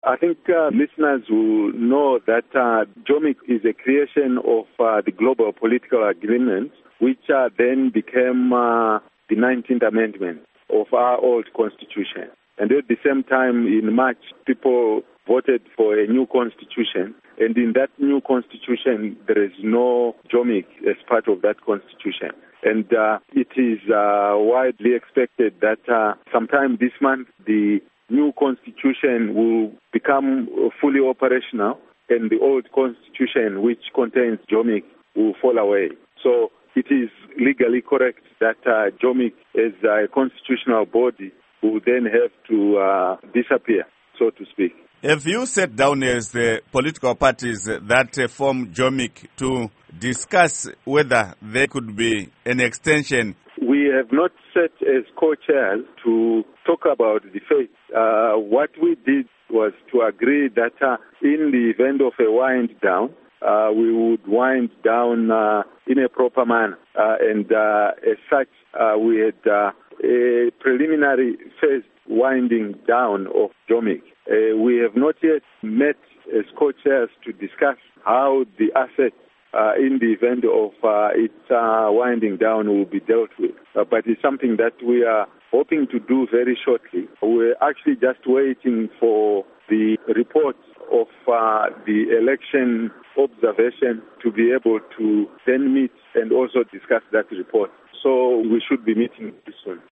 Interview With Elton Mangoma